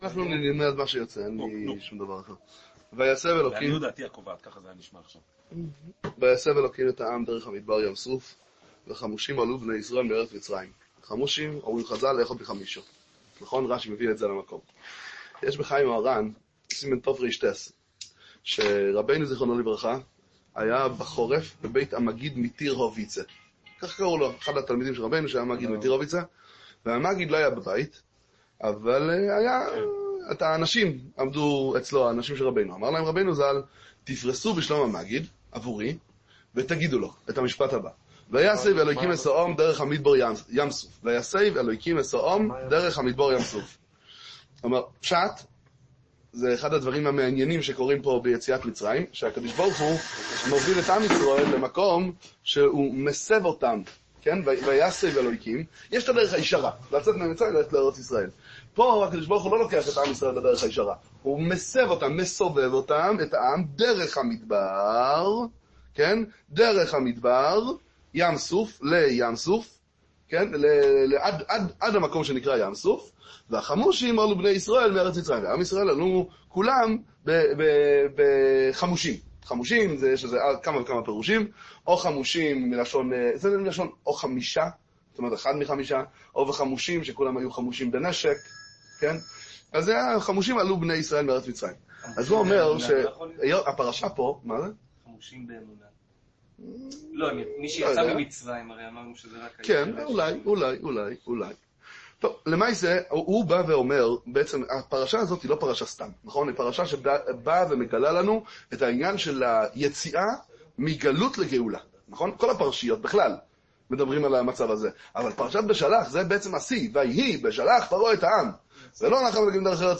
דבר תורה קצר מתורתו של רבי נחמן מברסלב זי"ע